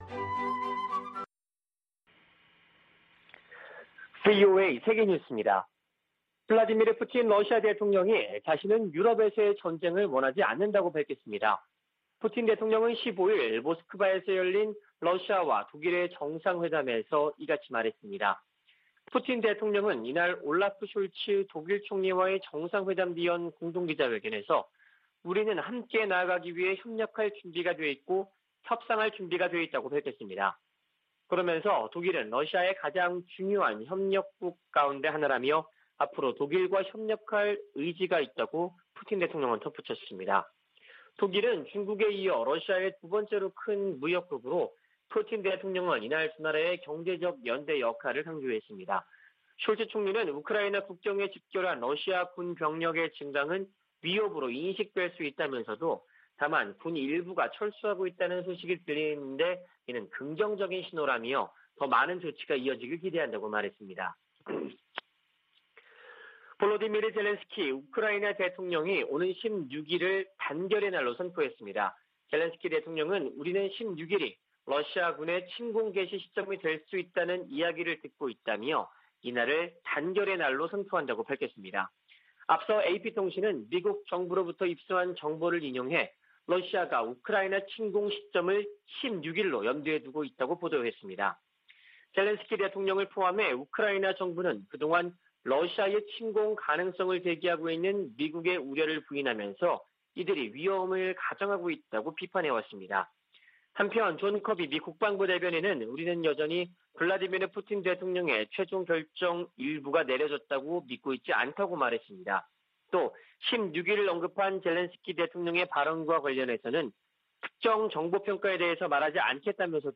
VOA 한국어 아침 뉴스 프로그램 '워싱턴 뉴스 광장' 2021년 2월 16일 방송입니다. 미 국무부는 북한 영변 핵 시설이 가동 중이라는 보도에 대해 북한이 비확산 체제를 위협하고 있다고 비판했습니다. 조 바이든 미국 대통령이 물러날 때 쯤 북한이 65개의 핵무기를 보유할 수도 있다고 전문가가 지적했습니다. 미한일이 하와이에서 북한 문제를 논의한 것과 관련해 미국의 전문가들은 3국 공조 의지가 확인됐으나, 구체적인 대응이 나오지 않았다고 평가했습니다.